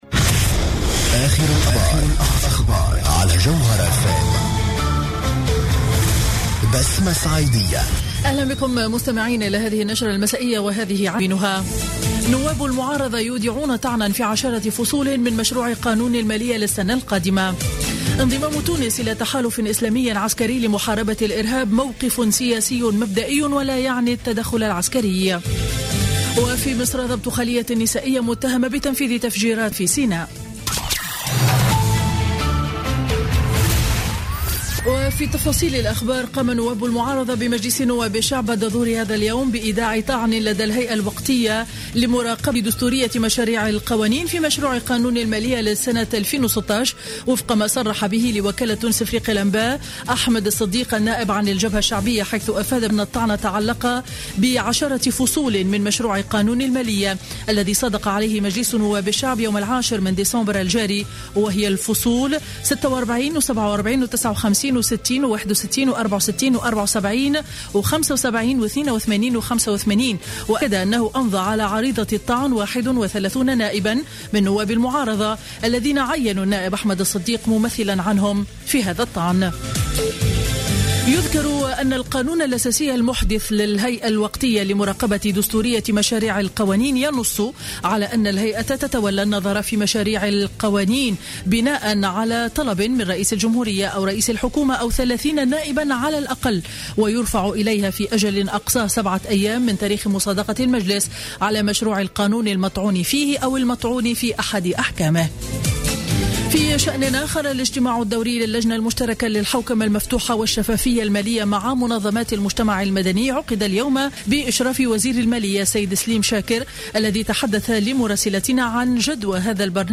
نشرة أخبار السابعة مساء ليوم الثلاثاء 15 ديسمبر 2015